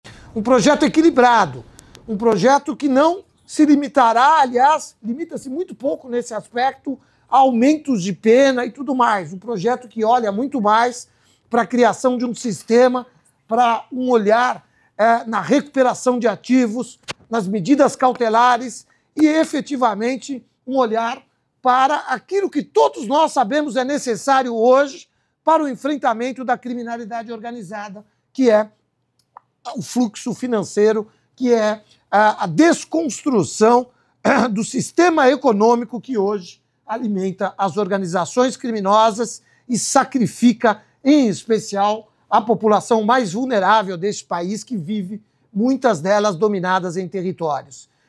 Mario Sarrubbo, secretário nacional de Segurança Pública, fala sobre a PEC da Segurança Pública e como ela propõe combater o crime organizado — Ministério da Justiça e Segurança Pública